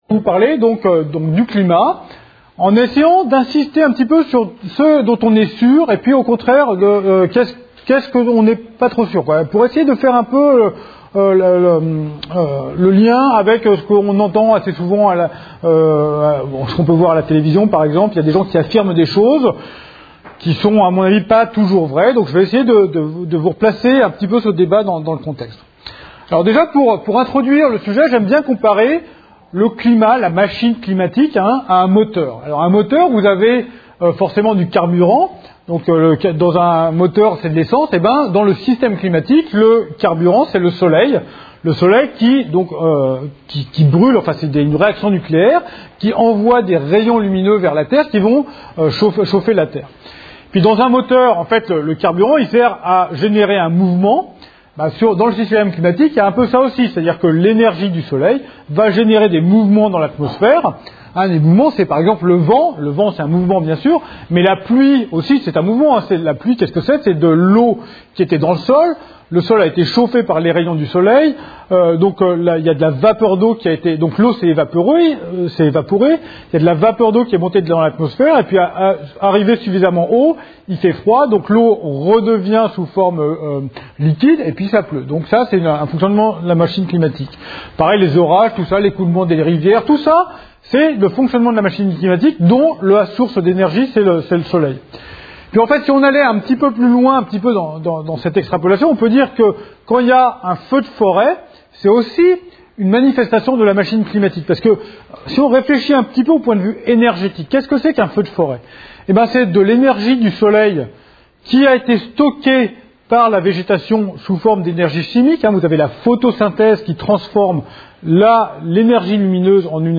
Une conférence de l'UTLS au lycée Le réchauffement climatique